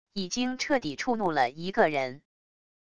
已经彻底触怒了一个人wav音频生成系统WAV Audio Player